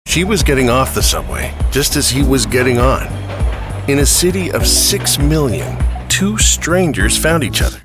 Voice Samples: Subway Romance
male